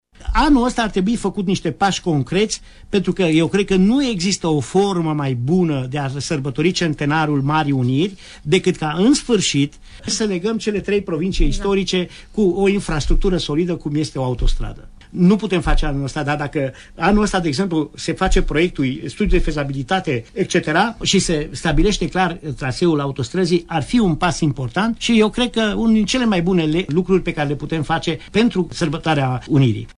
Cu toate acestea, în ultimii 10 ani nu au fost finalizate nici măcar studiile de fezabilitate și abia în vara anului trecut a primit certificatul de urbanism, a declarat la Părerea Ta, deputatul de Mureș Gheorghe Dinu Socotar: